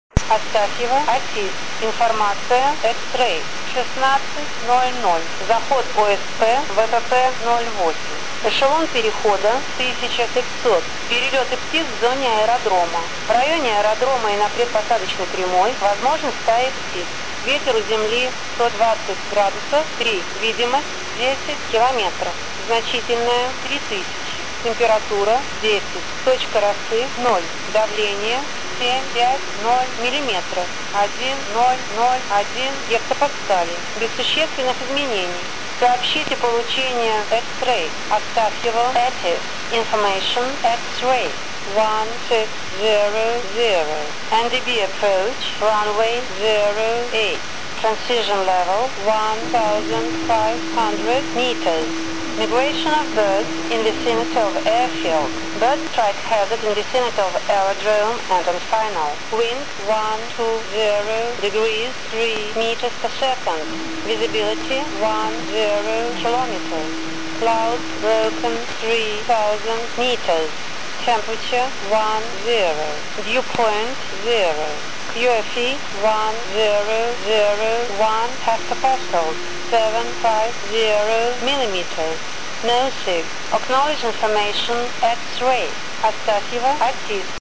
АТИС Остафьево, октябрь 2009г.
Начало » Записи » Записи радиопереговоров - авиация
Запись Остафьево ATIS, сделанная в октябре 2009г.
ostafevo-atis.mp3